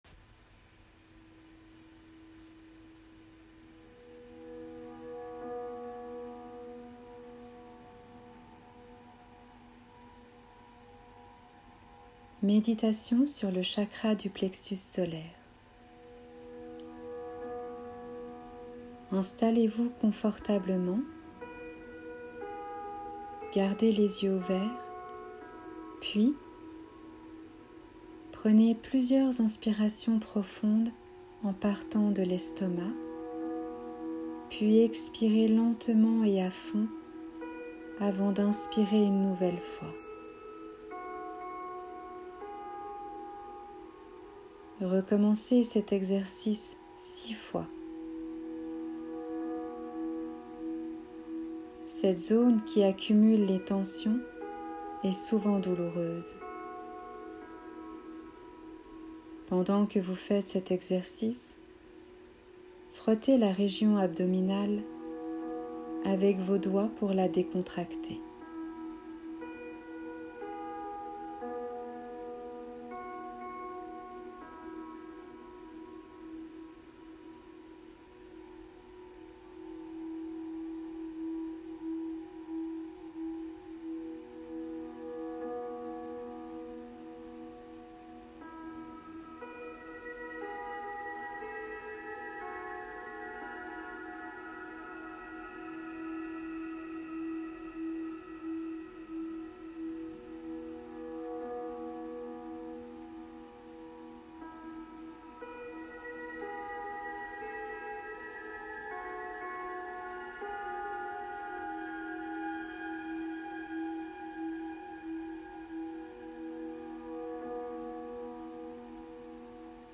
Méditation - Chakra du plexus solaire
meditation-chakra-plexus-solaire-mai2021.mp3